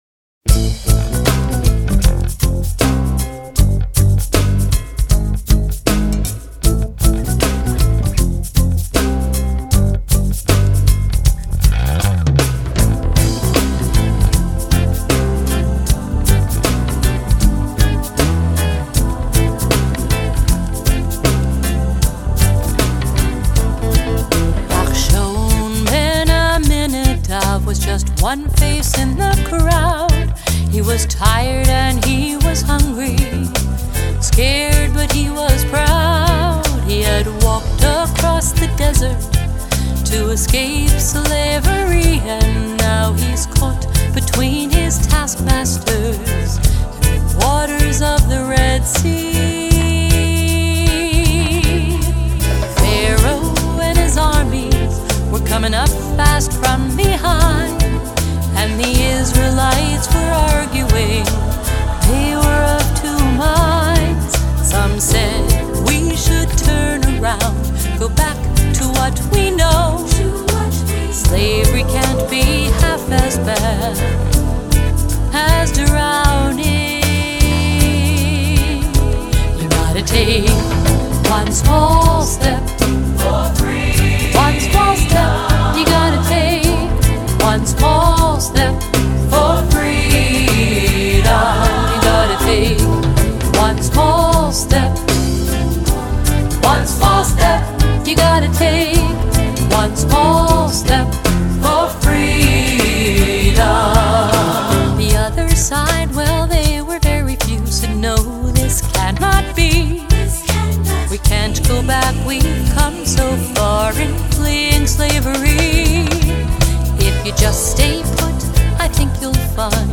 Internationally known contemporary Jewish music